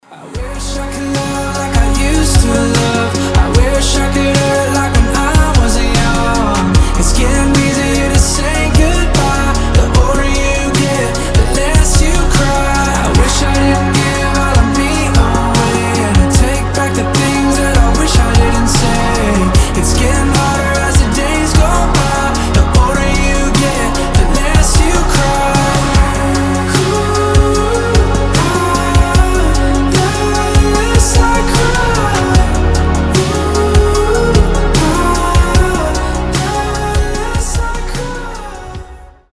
• Качество: 320, Stereo
красивый мужской голос
спокойные
медленные
indie pop
alternative
баллады